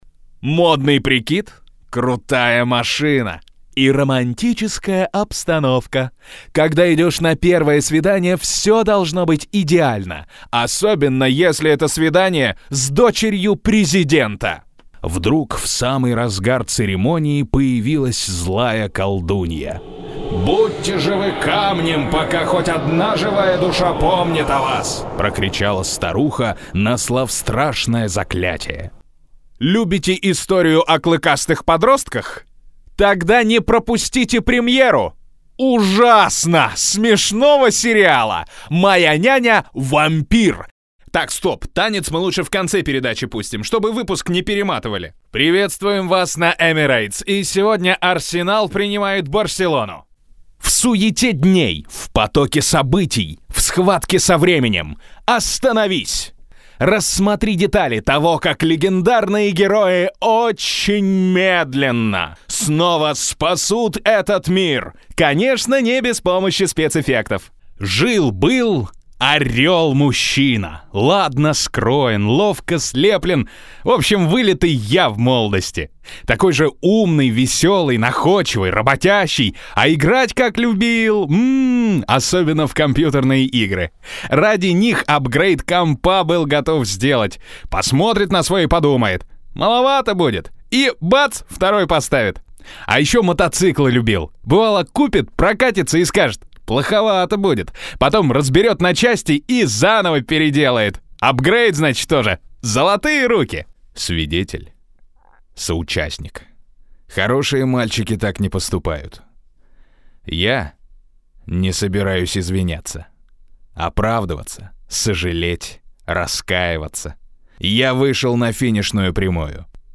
Демки из разных проектов Категория: Аудио/видео монтаж
Озвучиваю всё, от рекламных роликов до телепрограмм и документальных фильмов.